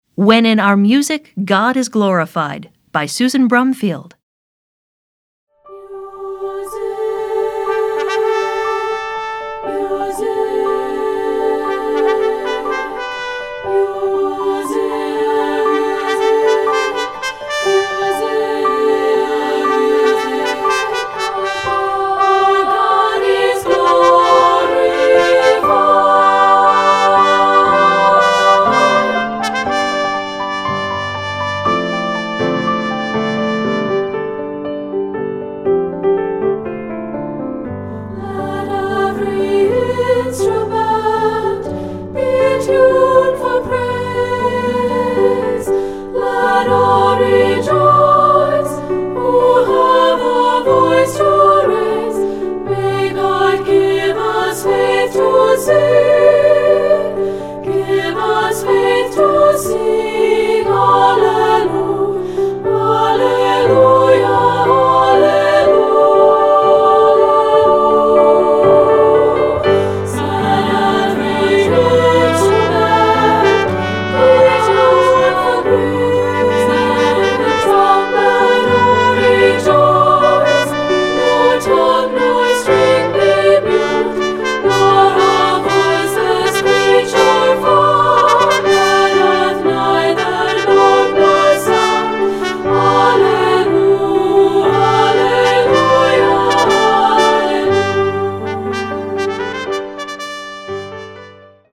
Voicing: 3-Part Treble